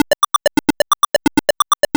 retro_synth_beeps_groove_04.wav